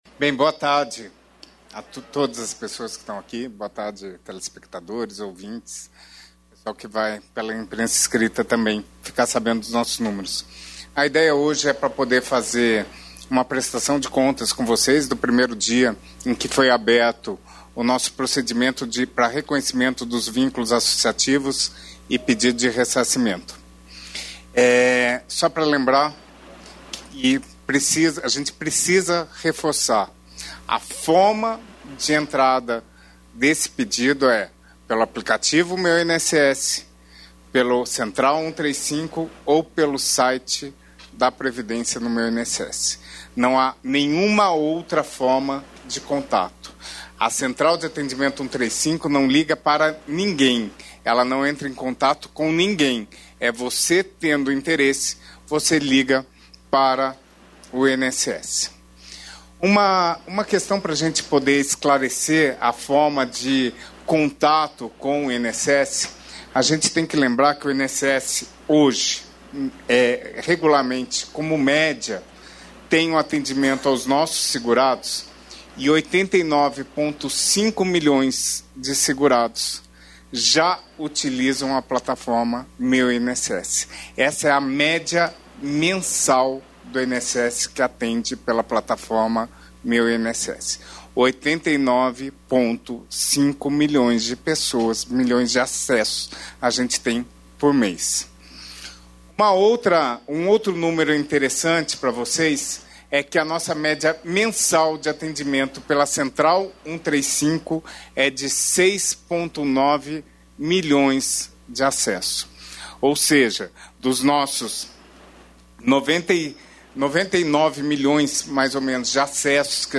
Íntegra da fala do presidente da Companhia Nacional de Abastecimento (Conab), Edegar Pretto, durante reunião em Porto Alegre nesta quinta-feira (15), sobre a abertura de edital para compra e doação de sementes e mudas por meio do Programa de Aquisição de Alimentos (PAA). Pretto também detalha a antecipação da compra de arroz e a entrega de cestas básicas a famílias afetadas pelas enchentes no Rio Grande do Sul.